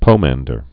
(pōmăndər, pō-măn-)